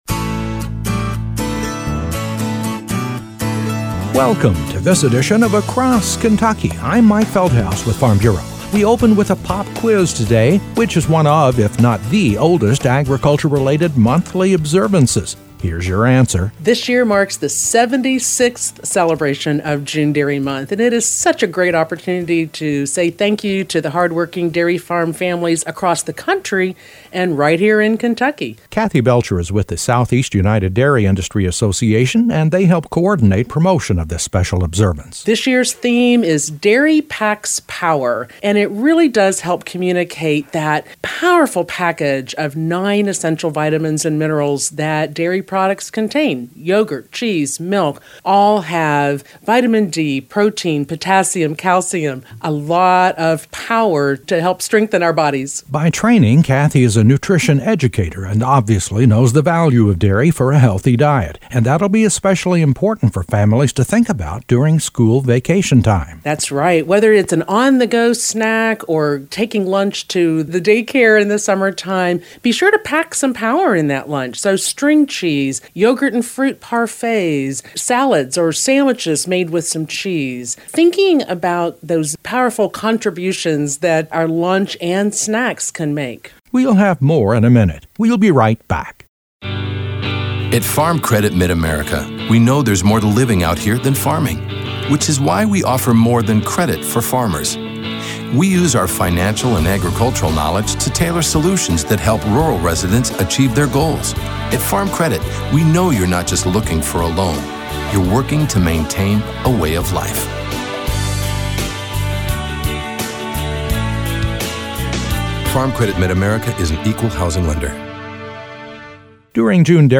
A feature report on this year’s June Dairy Month celebration in Kentucky.